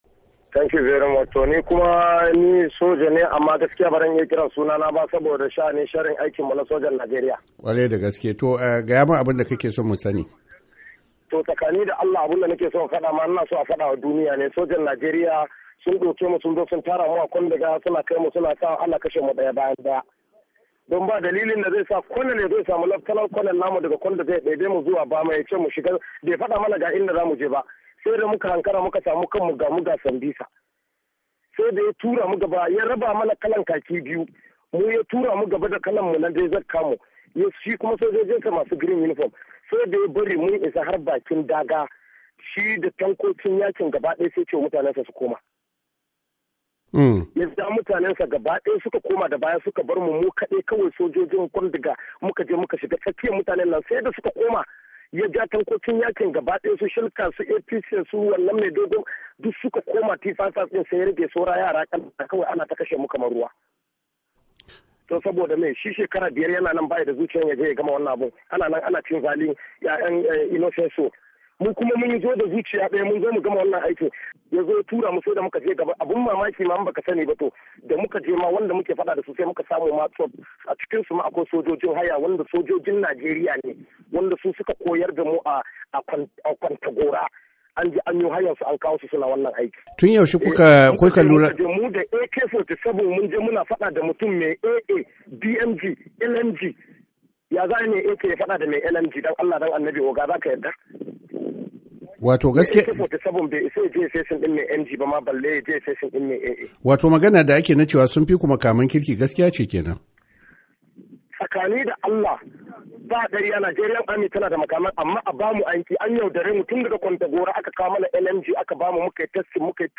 Hira Da Sojan Najeriya Kan Abubuwan Da Suke Faruwa A Borno - 4'27"